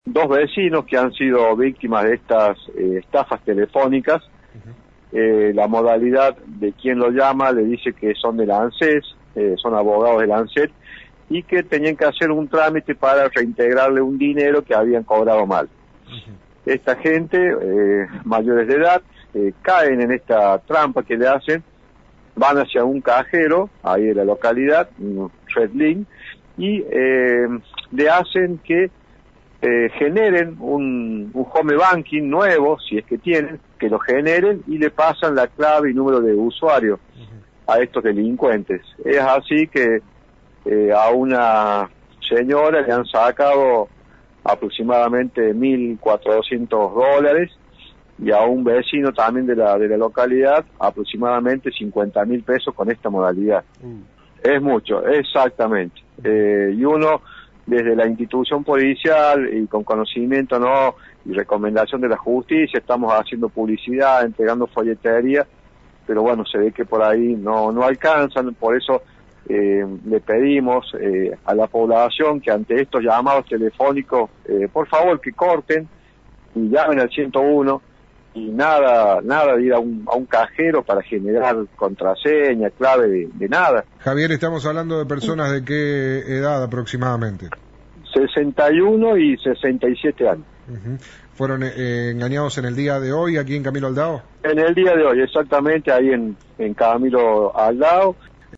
Escuchá la nota de «Radio Dos Mil» para Cadena de Noticias: